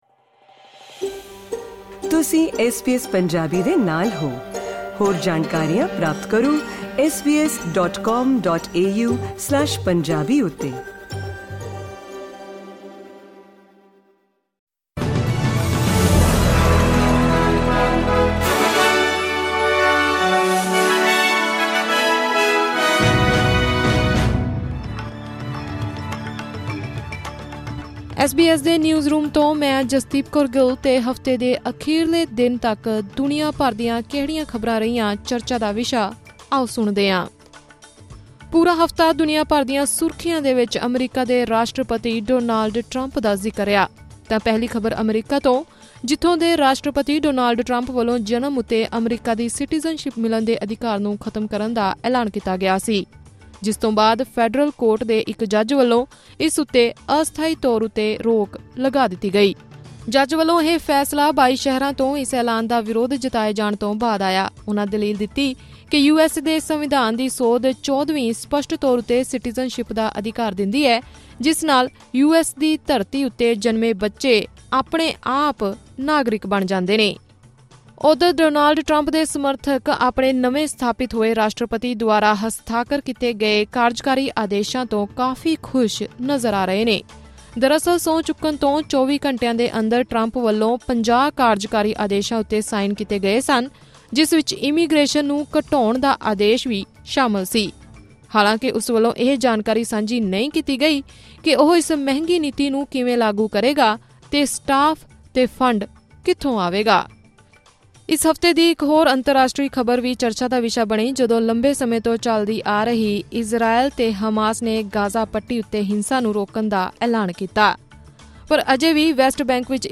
ਕੁੱਝ ਮਿੰਟਾਂ 'ਚ ਜਾਣੋ ਪੂਰੇ ਹਫਤੇ ਦੀਆਂ ਰਾਸ਼ਟਰੀ ਤੇ ਅੰਤਰਰਾਸ਼ਟਰੀ ਖ਼ਬਰਾਂ ਪੰਜਾਬੀ 'ਚ....